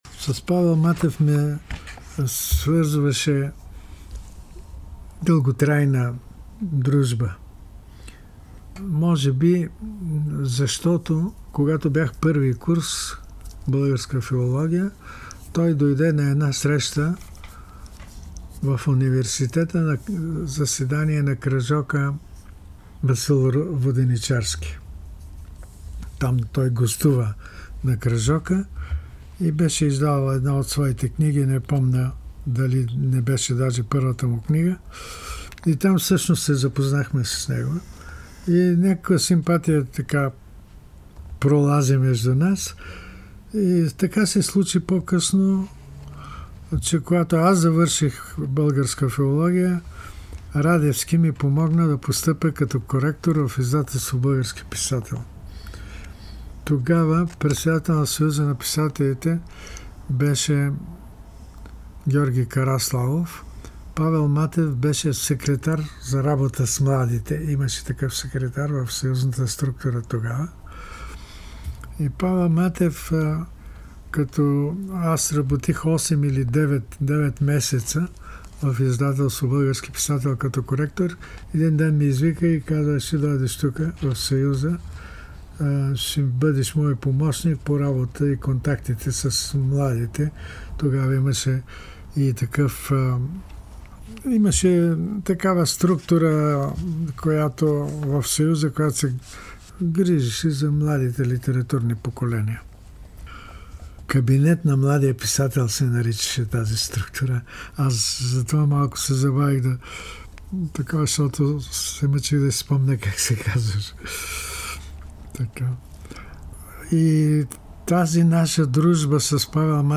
В запис от 2012 година, съхранен в Златния фонд на БНР, поетът Петър Караангов си спомня за дружбата с Павел Матев.